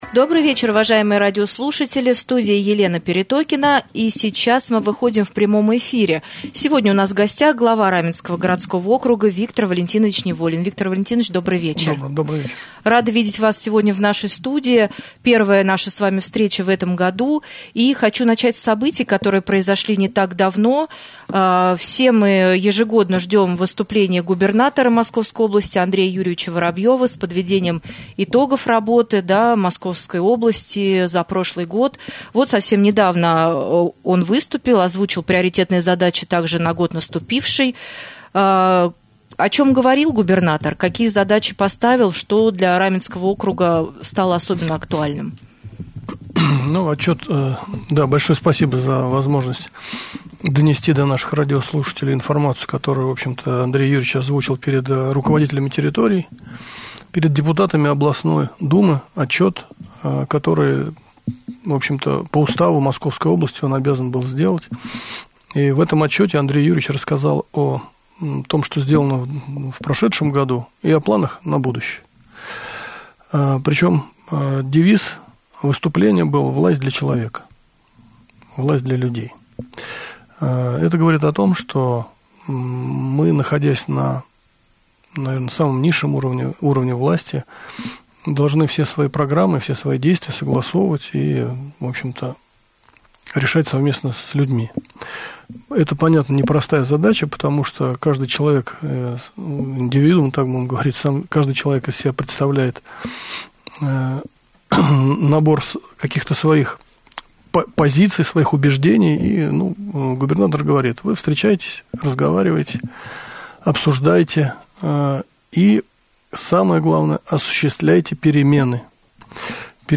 31 января, гостем прямого эфира на Раменском радио стал глава Раменского г.о. Виктор Неволин. Руководитель муниципалитета ответил на вопросы жителей, рассказал об отчетах по итогам работы ТУ округа в 2021 году, поднял актуальные темы.